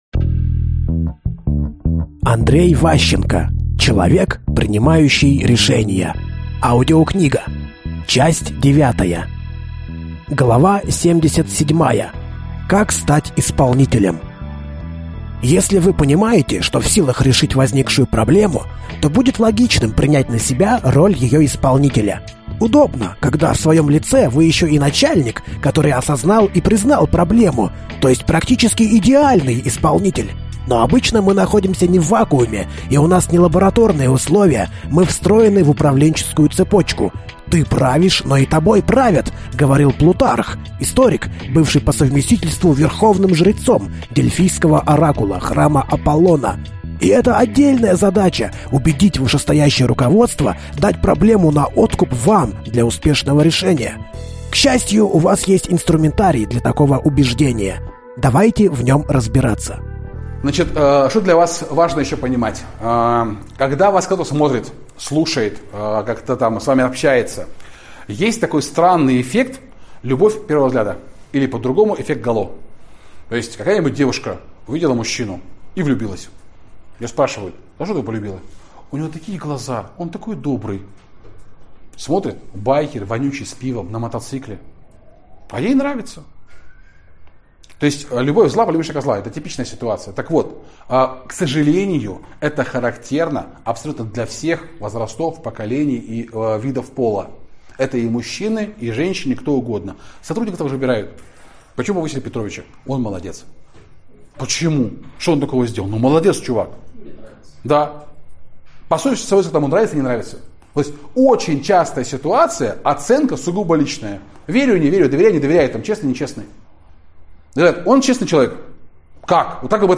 Аудиокнига Человек, принимающий решения. Часть 9 | Библиотека аудиокниг